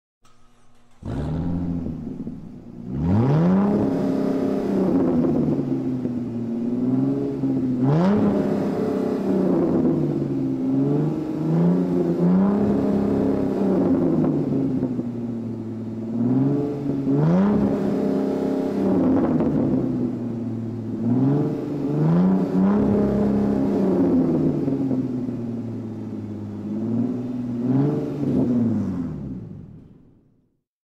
Car Engine Sound Button - Free Download & Play